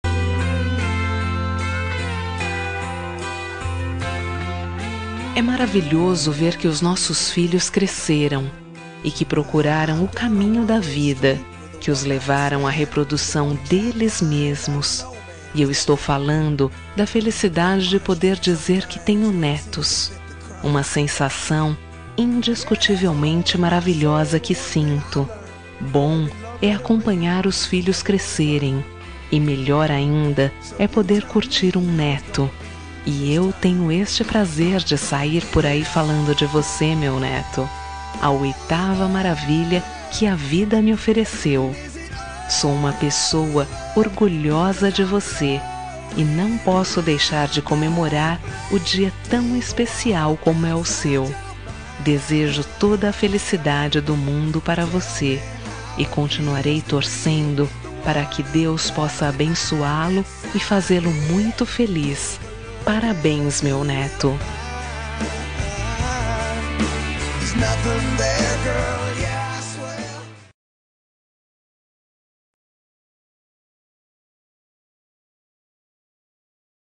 Aniversário de Neto – Voz Feminina – Cód: 131039